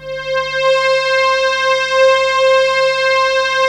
Index of /90_sSampleCDs/USB Soundscan vol.05 - Explosive Jungle [AKAI] 1CD/Partition D/03-DEEP PADS